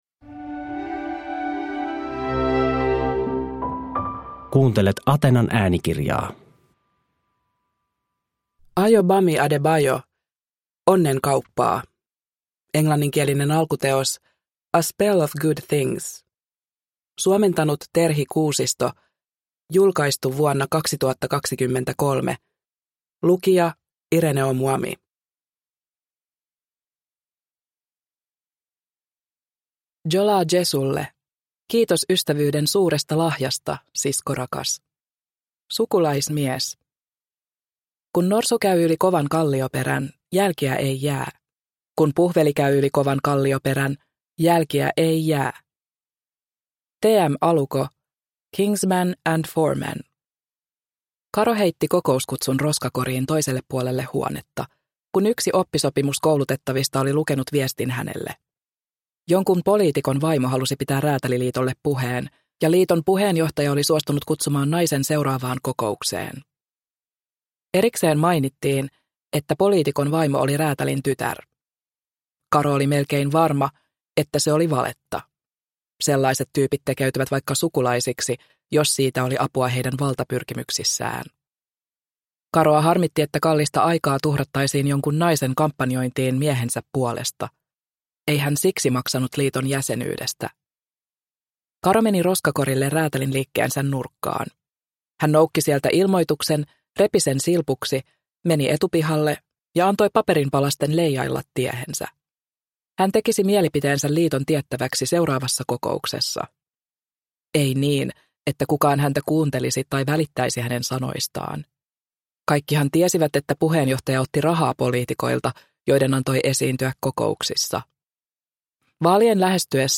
Onnenkauppaa – Ljudbok – Laddas ner